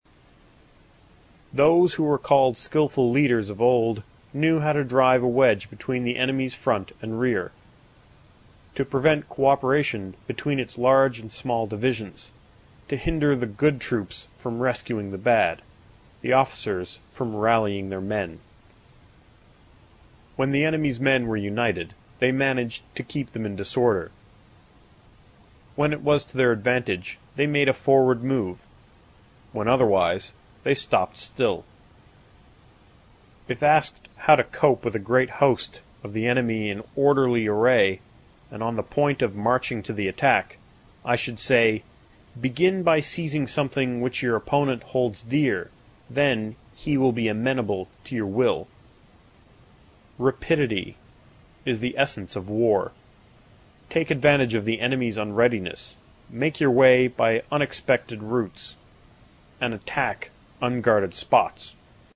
有声读物《孙子兵法》第63期:第十一章 九地(2) 听力文件下载—在线英语听力室